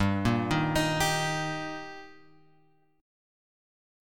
Gm#5 chord {3 1 1 x 4 3} chord